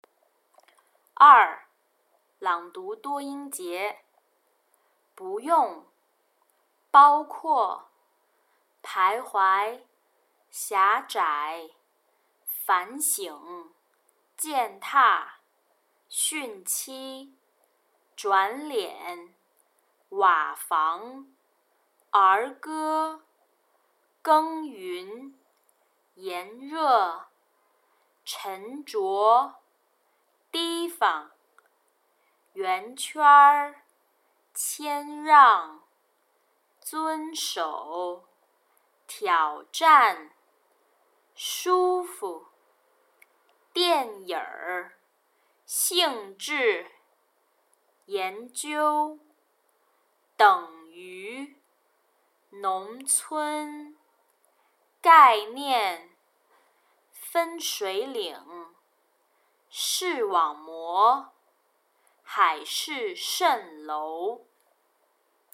Tasks 1&2 Word Reading
Beijing Sample: